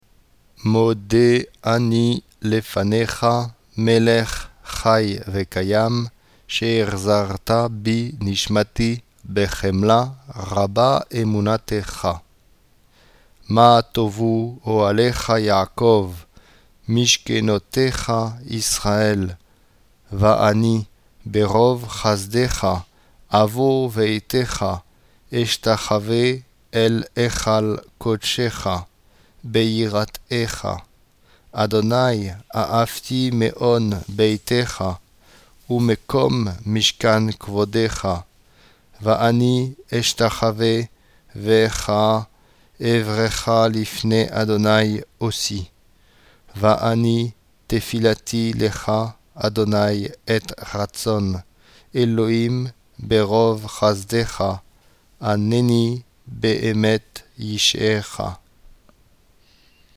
Pour permettre un meilleur apprentissage, nous vous proposons d’écouter les textes de Tefilati, lentement d’abord, chantés ensuite.
Mode Ani lent p.6
06_modeani_lent.mp3